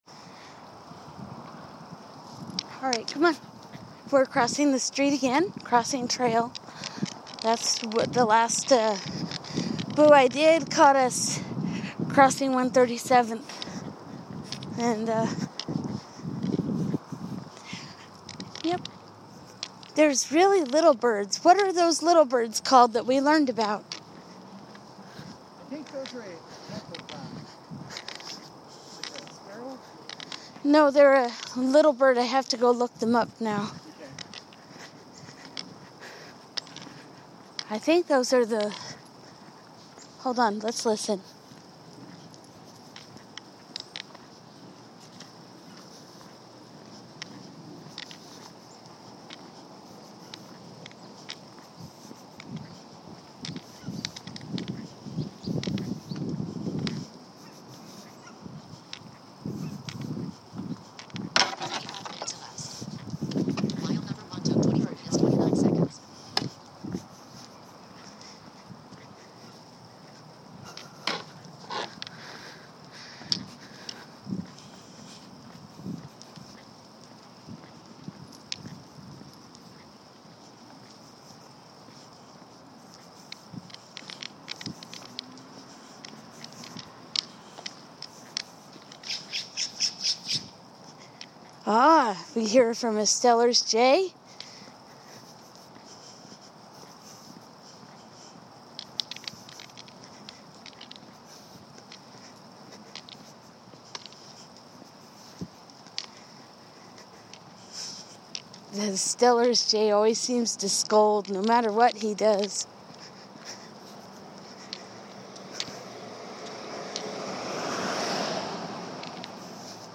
We end our walk and I show a little bit about what a cedar waxwing is supposed to sound like from acedia have. We resume recording in the backyard amidst wind chimes and the breeze picking up.
We do get a few sparse birds here and there, but more pronounced is the wind and the chimes.